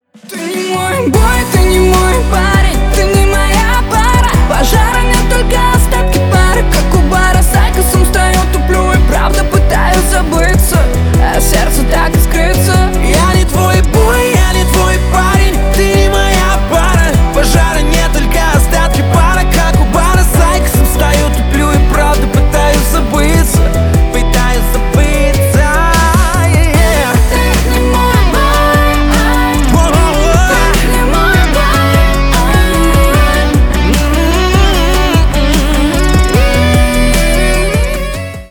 дуэт
поп